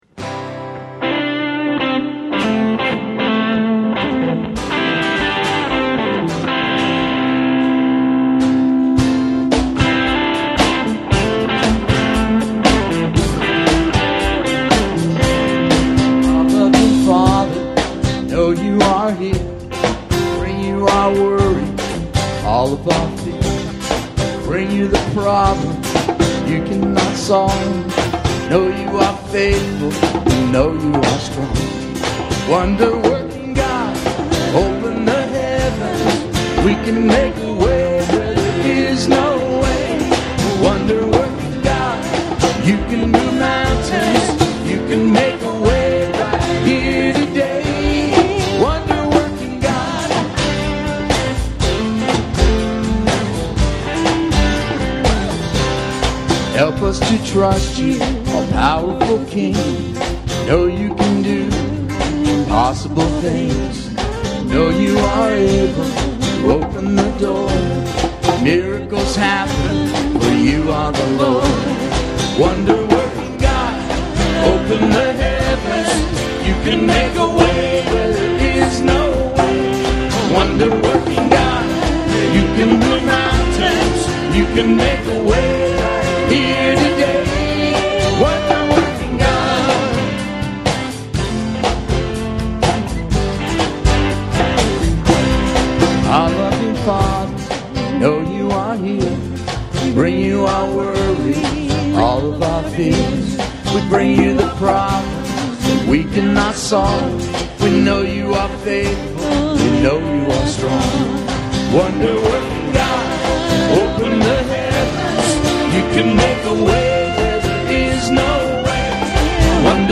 Recorded live
at Celebration Center in Redlands, CA.